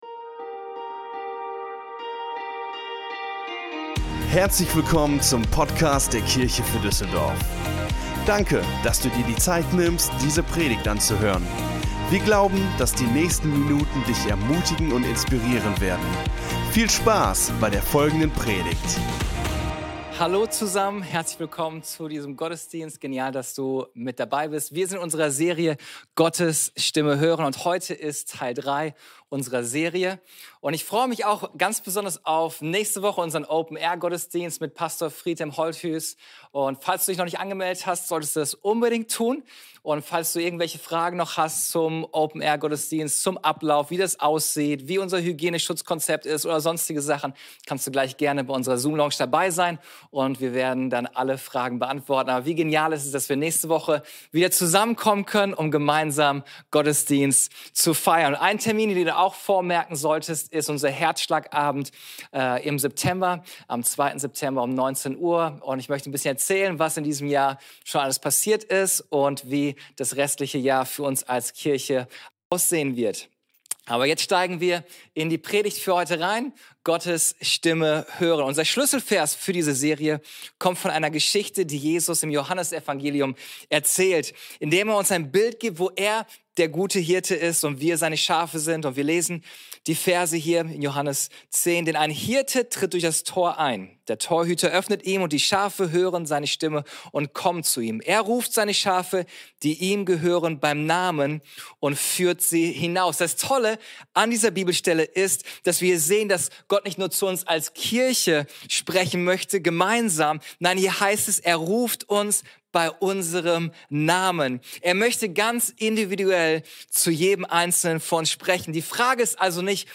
Der dritte Teil unserer Predigtserie: "Gottes Stimme hören" Folge direkt herunterladen